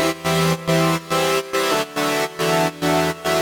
Index of /musicradar/sidechained-samples/140bpm
GnS_Pad-MiscA1:4_140-E.wav